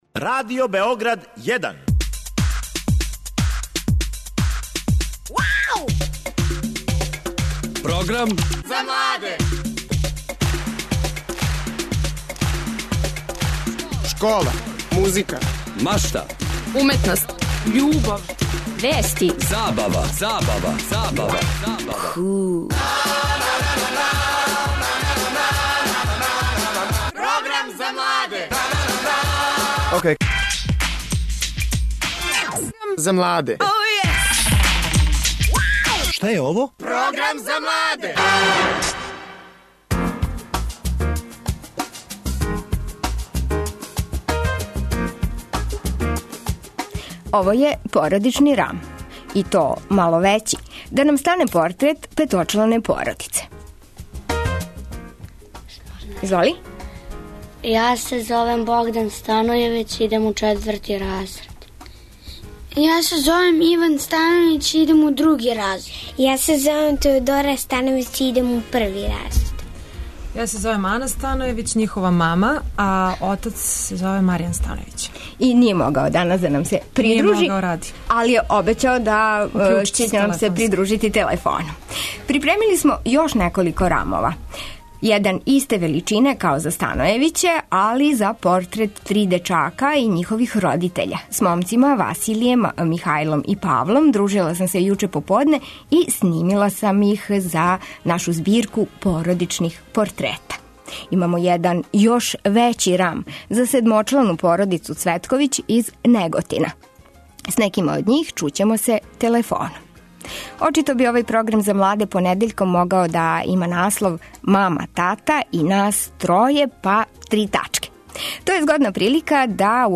У емисији говоримо о односима родитеља и деце, њиховим свакодневним навикама, проблемима, лепим породичним тренуцима... У госте нам долазе мама, тата и троје.. четворо деце.